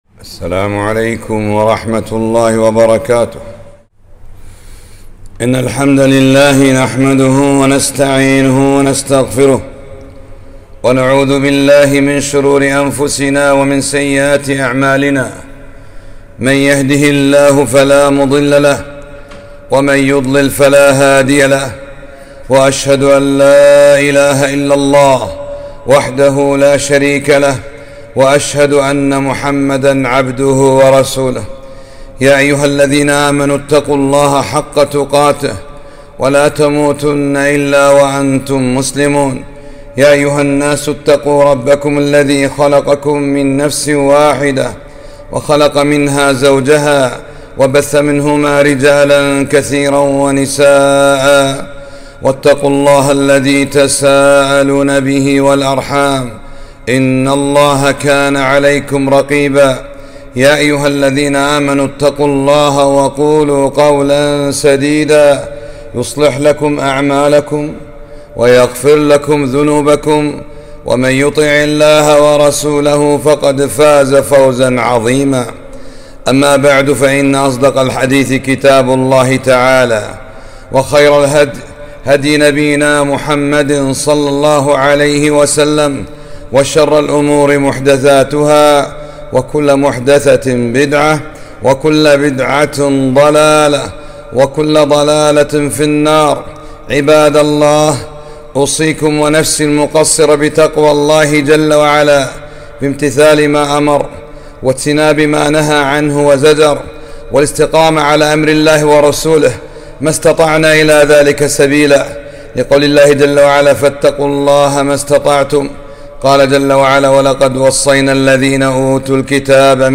خطبة - (كن صاحبًا للقرآن)